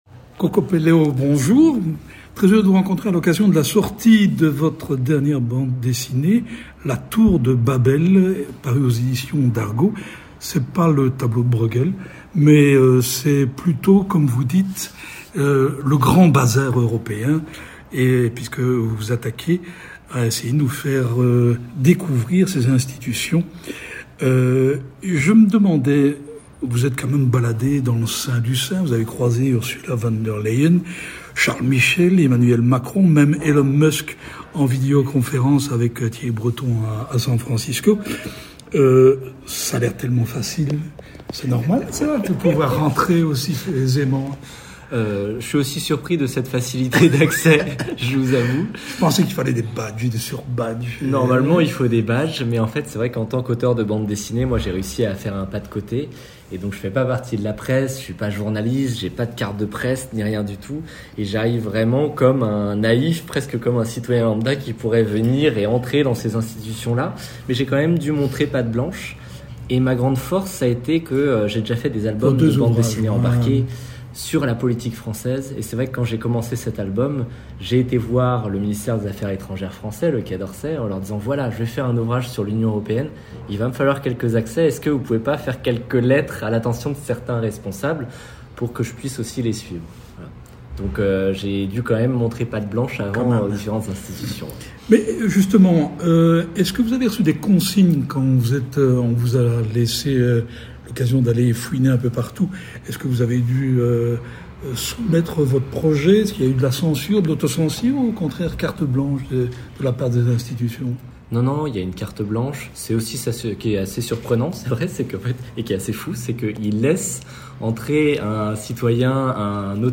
Rencontre avec l’auteur.